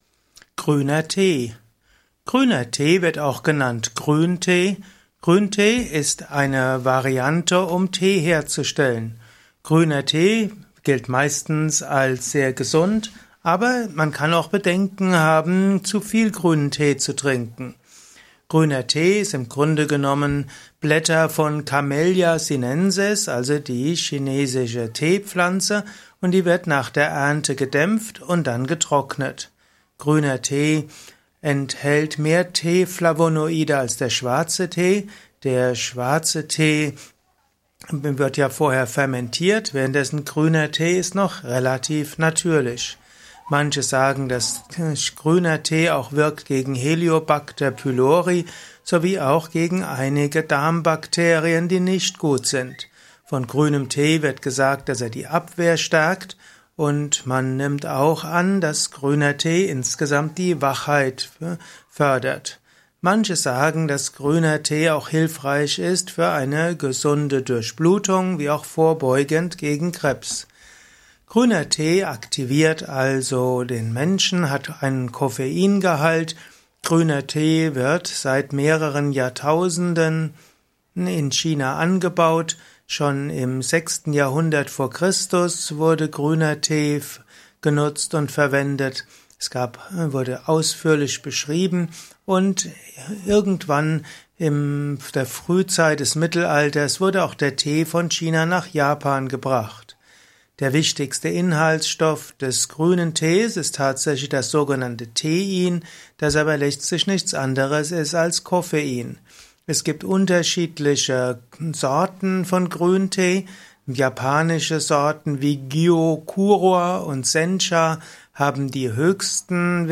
Simple und komplexe Infos zum Thema Grüner Tee in diesem Kurzvortrag